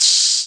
Index of /90_sSampleCDs/Sample Magic - Classic Chicago House/Drum Hits/hats
cch_hat_one_shot_open_high_hiss_station.wav